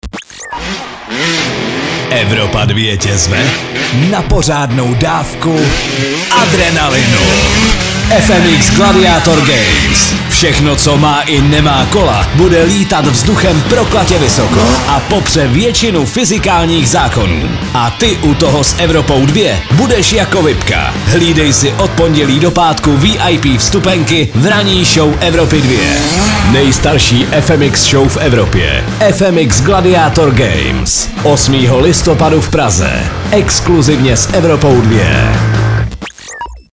liner_fmx_gladiator_games_soutez.mp3